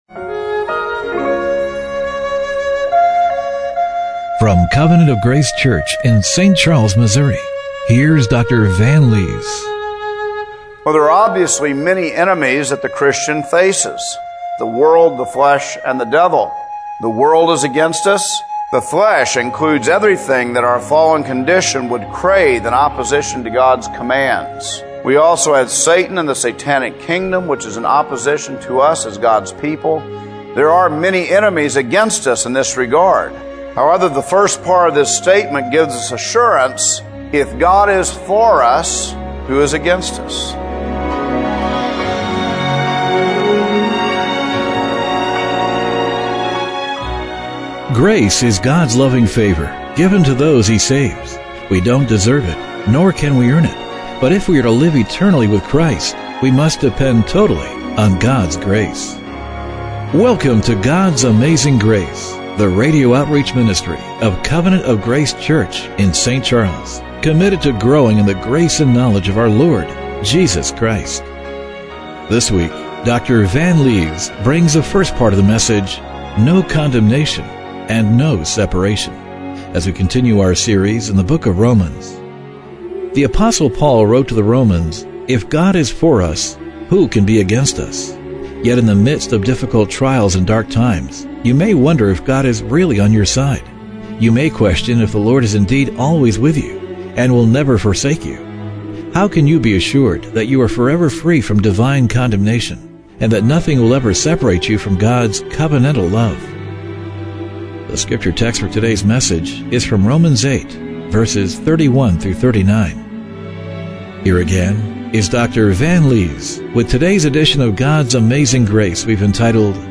Service Type: Radio Broadcast